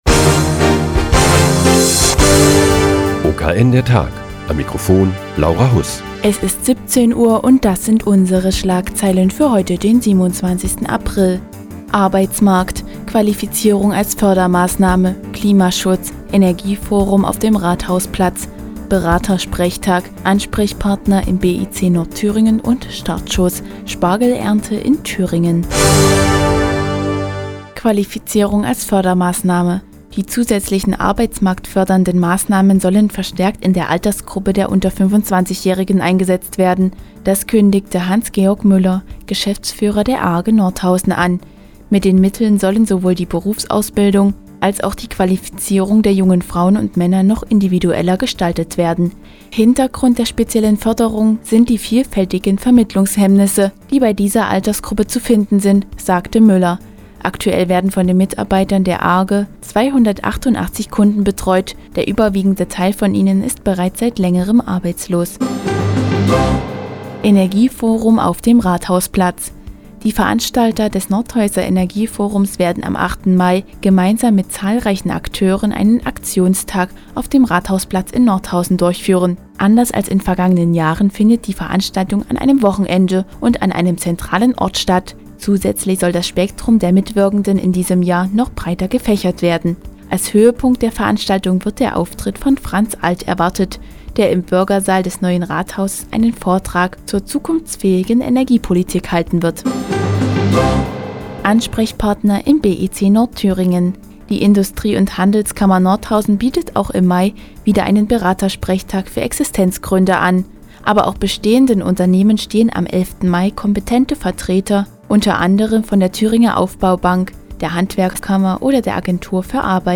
Die tägliche Nachrichtensendung des OKN ist nun auch in der nnz zu hören. Heute geht es um den Beratersprechtag für Existenzgründer im BIC Nordthüringen und die Thüringer Spargelernte.